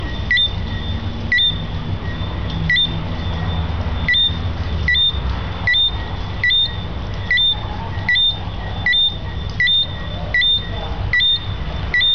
Jamaican tree frog ringtone free download
Animals sounds